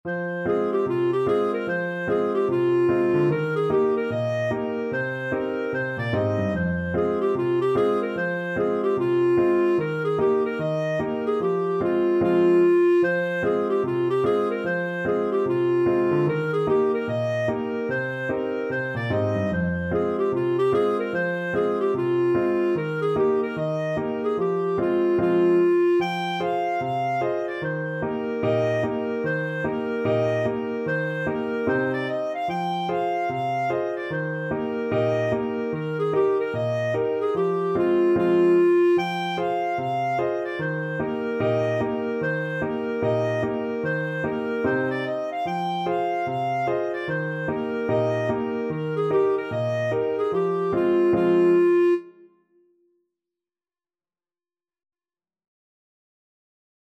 Clarinet
4/4 (View more 4/4 Music)
Eb major (Sounding Pitch) F major (Clarinet in Bb) (View more Eb major Music for Clarinet )
Irish